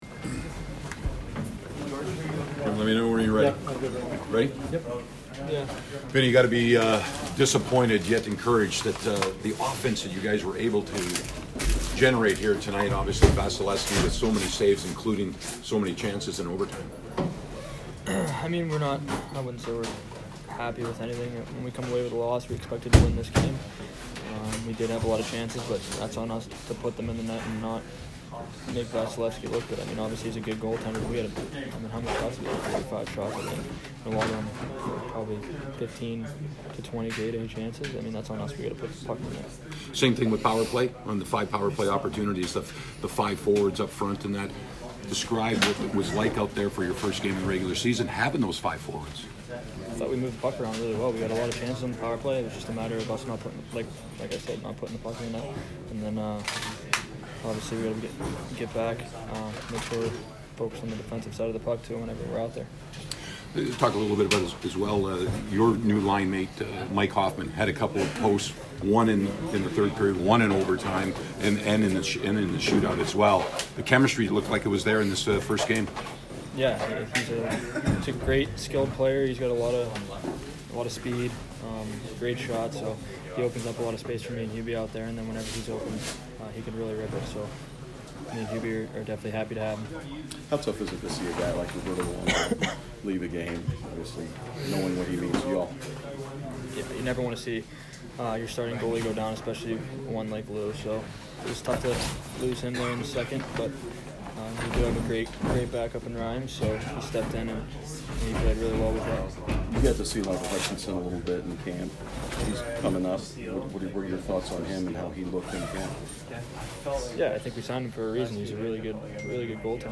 Vincent Trocheck post-game 10/6